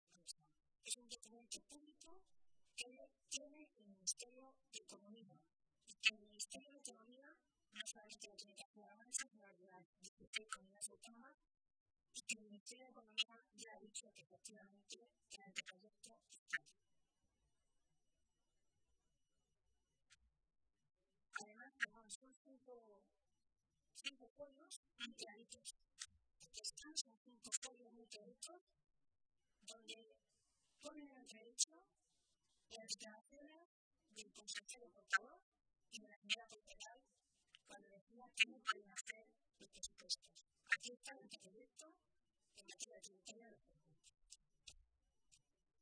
Milagros Tolón, portavoz de Empleo del Grupo Socialista
Cortes de audio de la rueda de prensa